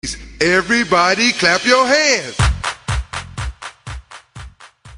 Play, download and share Fade Clap Ur Hands original sound button!!!!
ice_cream_clap_fade.mp3